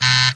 buzz.wav